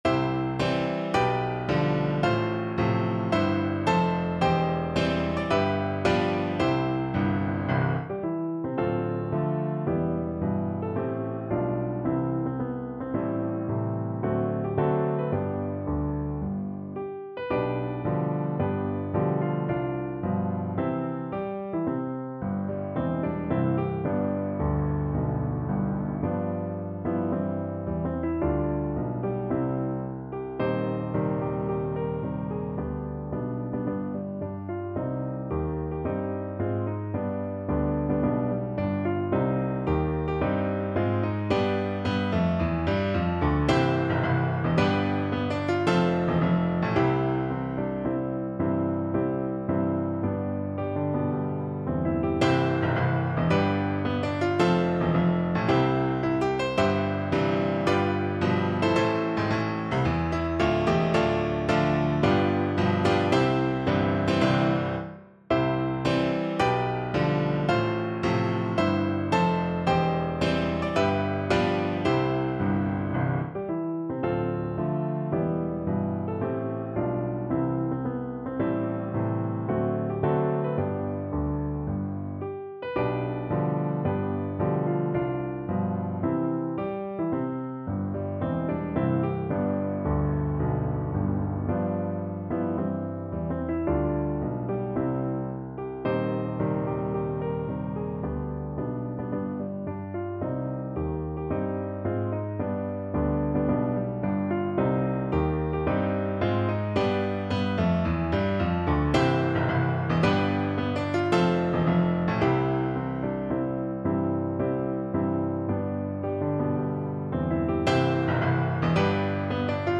C major (Sounding Pitch) (View more C major Music for Violin )
4/4 (View more 4/4 Music)
Marcial = 110 Marcial